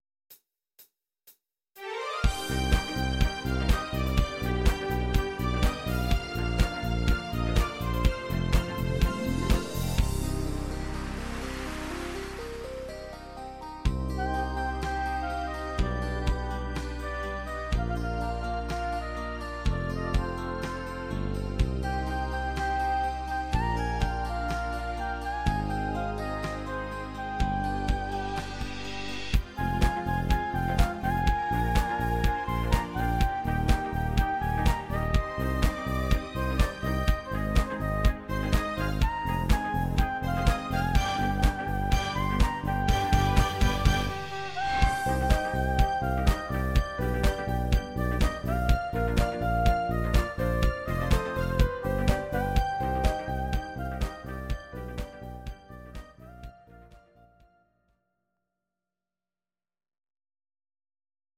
Audio Recordings based on Midi-files
Musical/Film/TV, German, 2000s